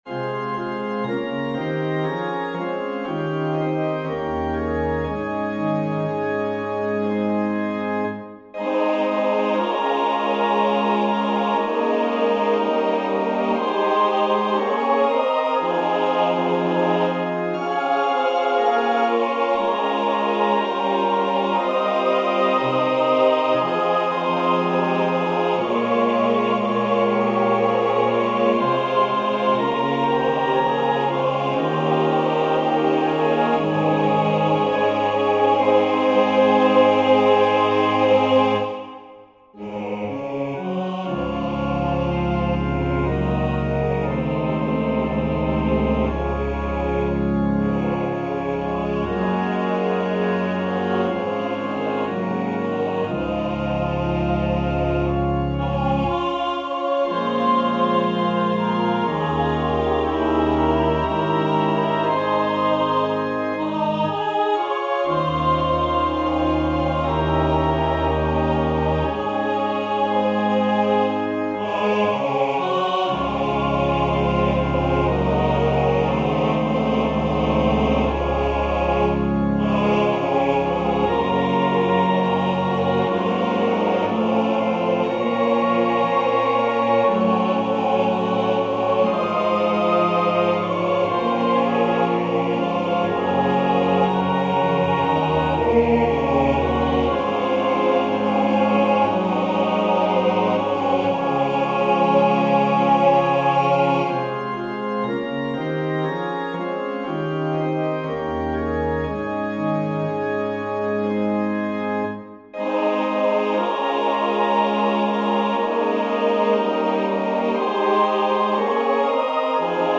SATB , Organ/Organ Accompaniment